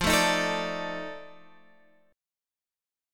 F13 chord